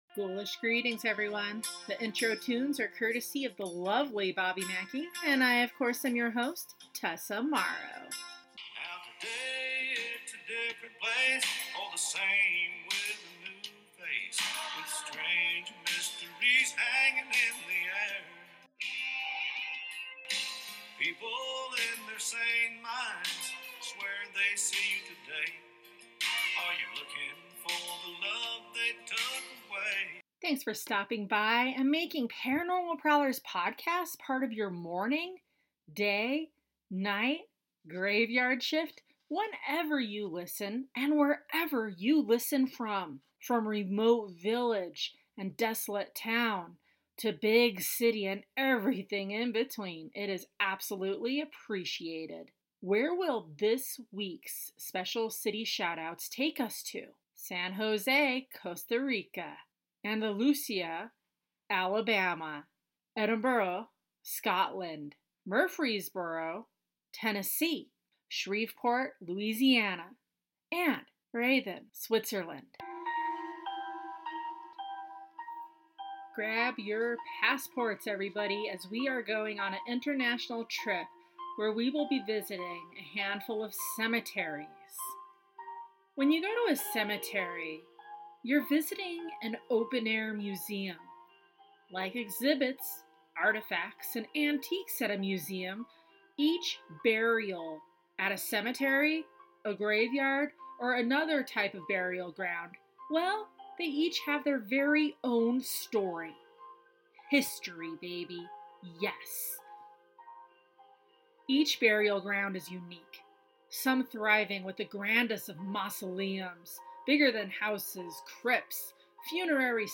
Train passing Yuma Territorial Prison (Arizona) Thunder storm (Colorado)